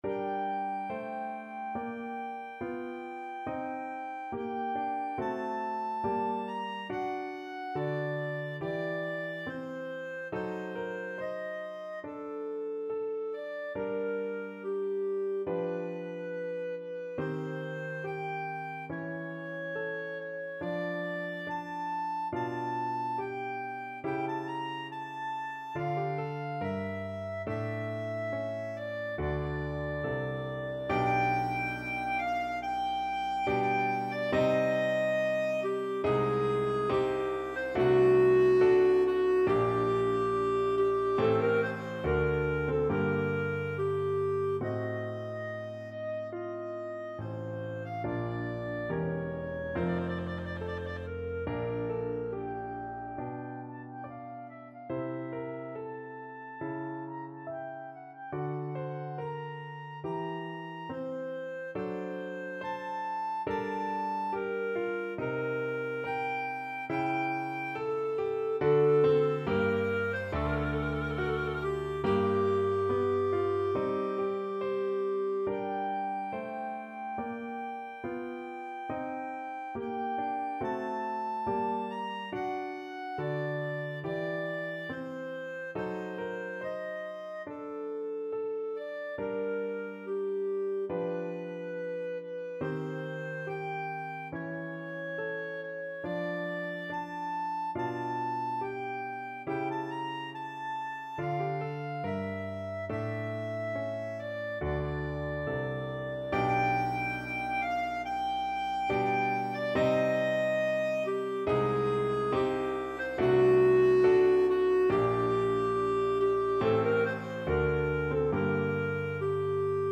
Classical Purcell, Henry Trio Sonata in G minor, Z.780 Clarinet version
Clarinet
4/4 (View more 4/4 Music)
Adagio =70
Bb major (Sounding Pitch) C major (Clarinet in Bb) (View more Bb major Music for Clarinet )
Classical (View more Classical Clarinet Music)
trio-sonata-in-g-minor-z-780_CL.mp3